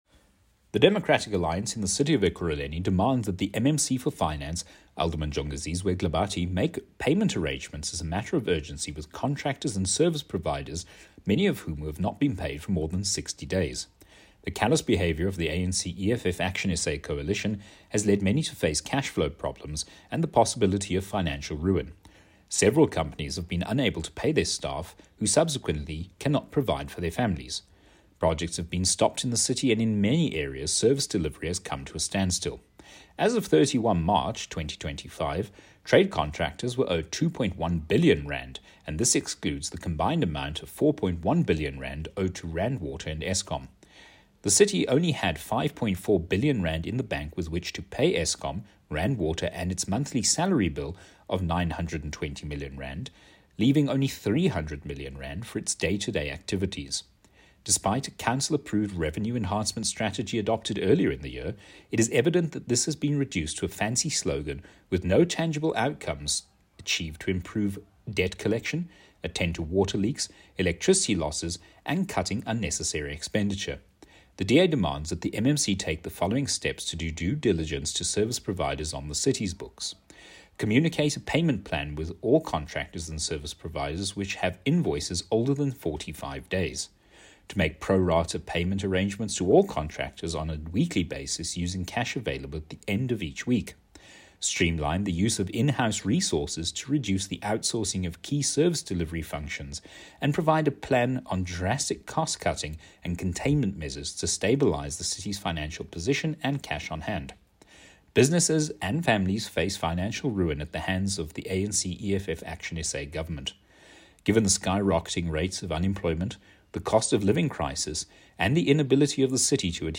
Note to Editors: Please find an English soundbite